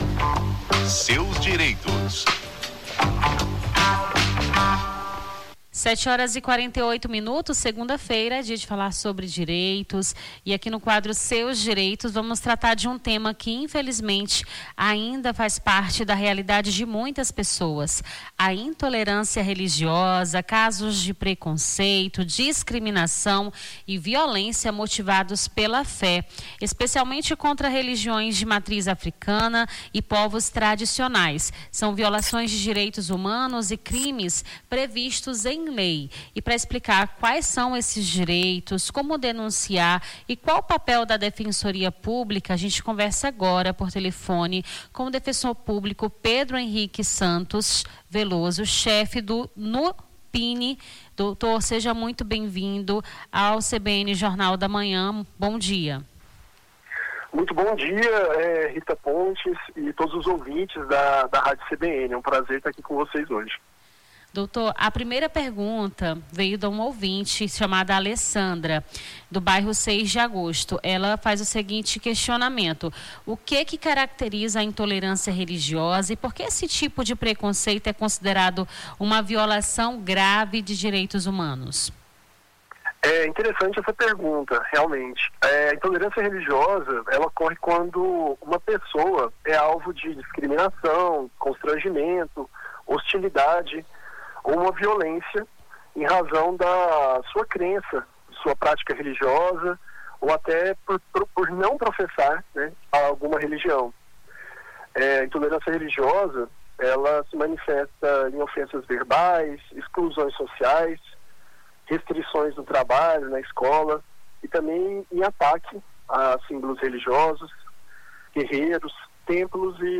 conversamos com o defensor público